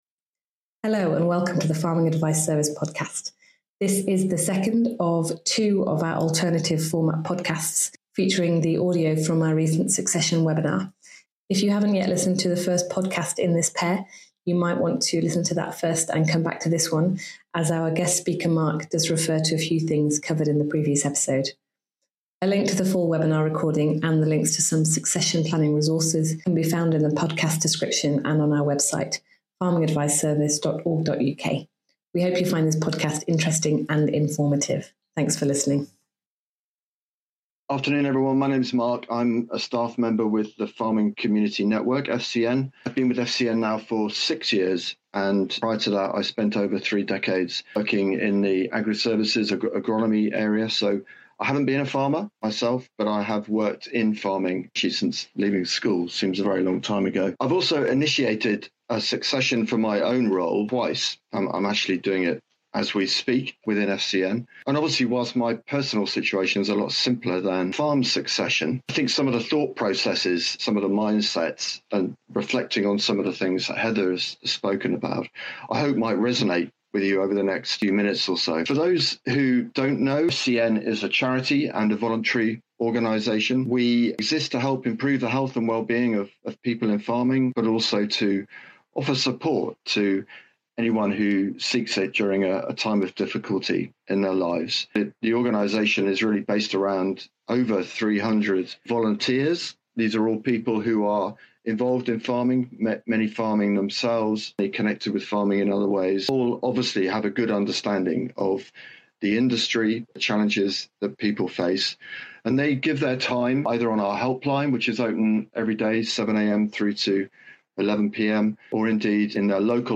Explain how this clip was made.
Taken from our Succession webinar